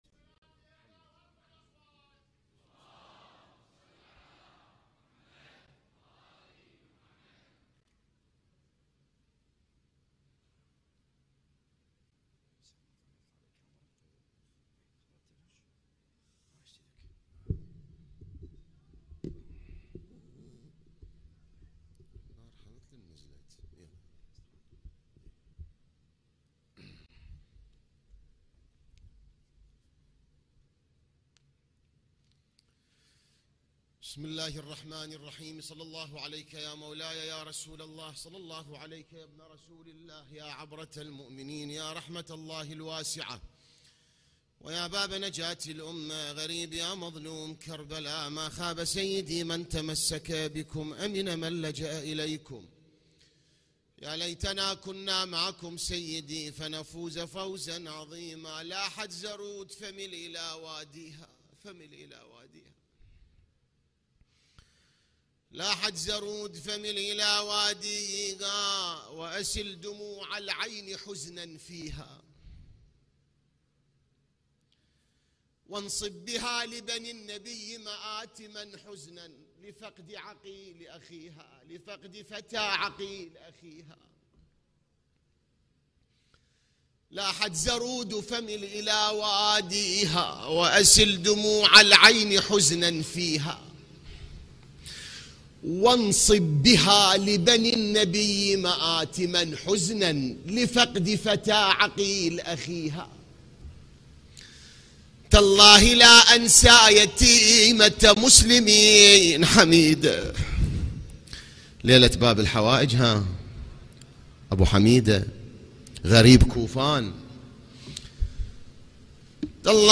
ليلة ٥ محرم ١٤٤٦هـ || هيئة الزهراء للعزاء المركزي في النجف الاشرف.
الان-مباشرة-ليلة-٥-محرم-١٤٤٦هـ-هيئة-الزهراء-للعزاء-المركزي-في-النجف-الاشرف.mp3